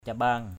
/ca-ɓa:ŋ/ 1.